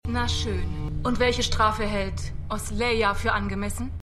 The100_5x02_WuetenderAzgedaKrieger.mp3